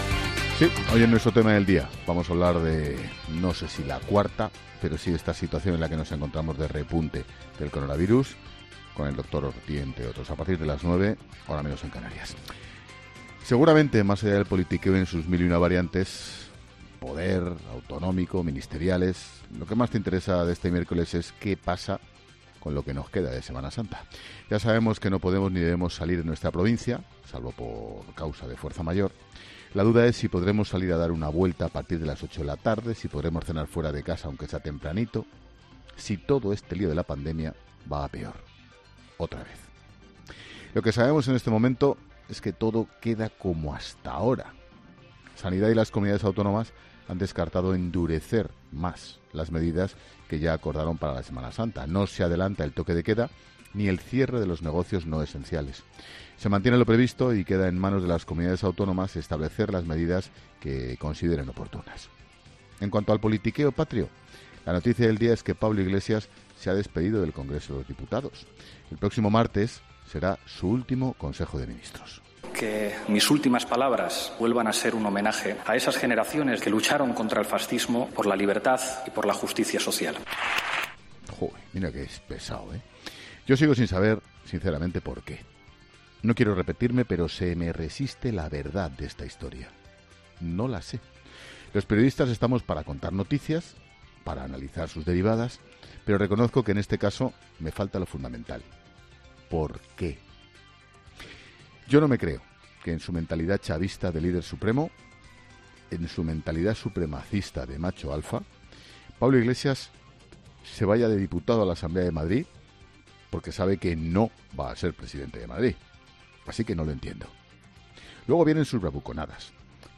Monólogo de Expósito
El director de 'La Linterna', Ángel Expósito, analiza las principales noticias de este miércoles